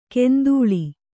♪ kendūḷi